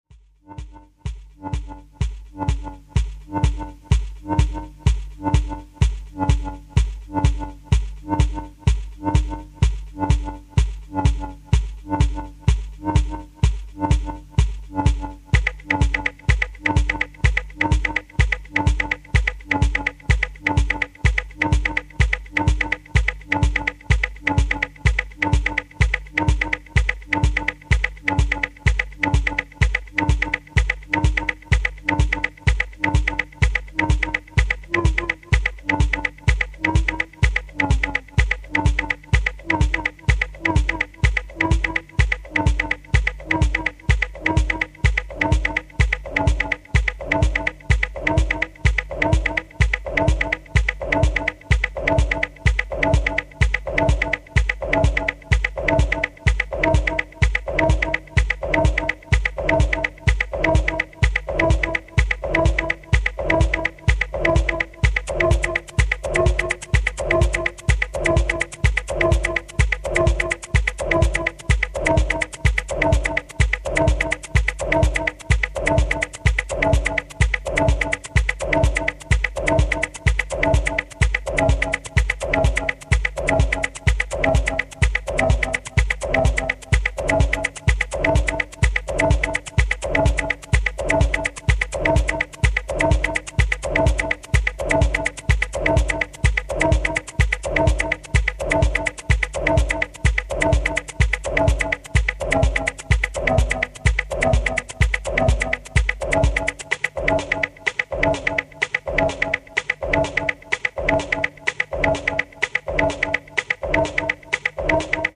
ホーム > TECHNO/ELECTRO > V.A.